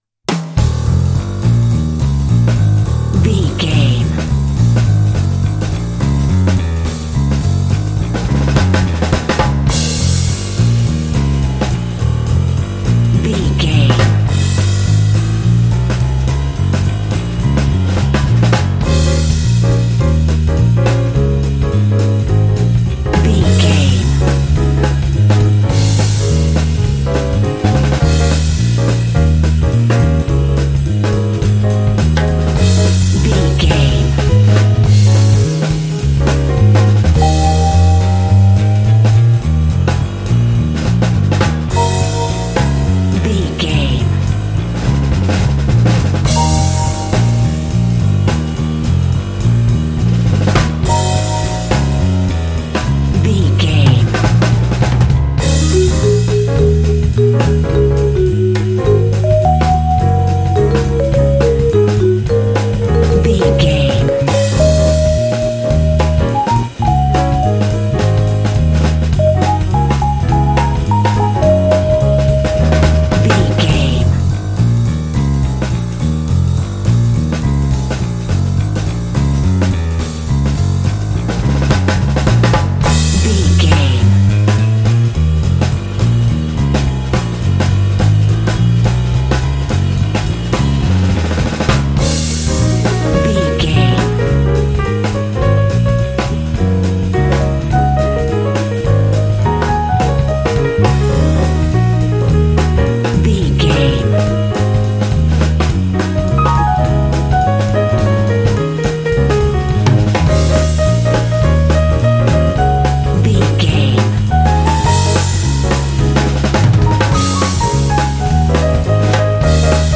Aeolian/Minor
E♭
energetic
groovy
lively
bass guitar
piano
drums
jazz
big band